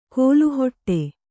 ♪ kōlu hoṭṭe